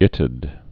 (ĭtəd)